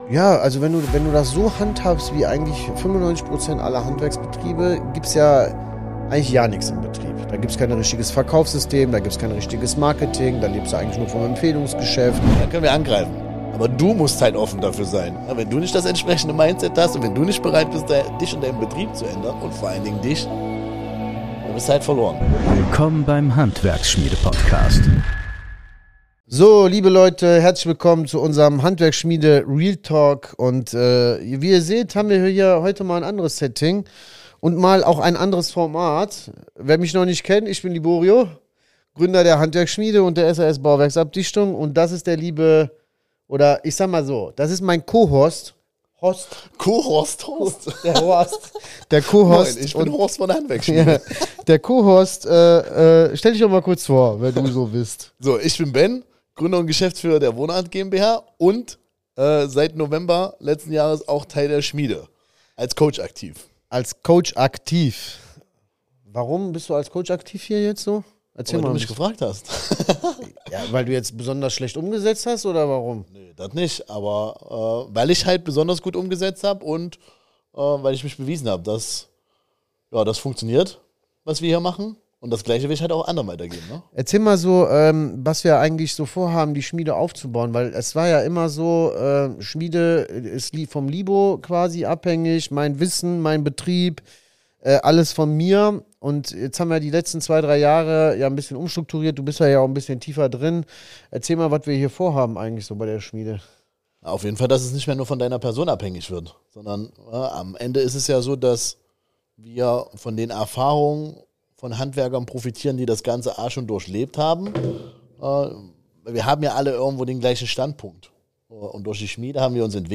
Warum der Bauchladen das größte Problem ist: So verbrennen Handwerker Umsatz | Interview